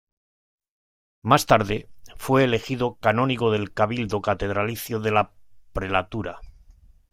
ca‧nó‧ni‧go
/kaˈnoniɡo/